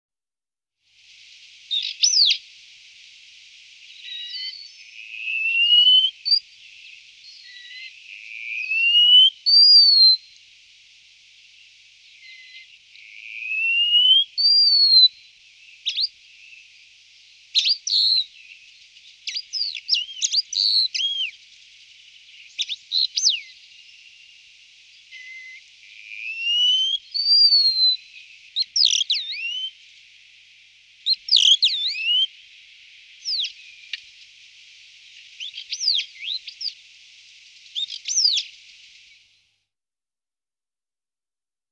Canto-treparriscos.m4a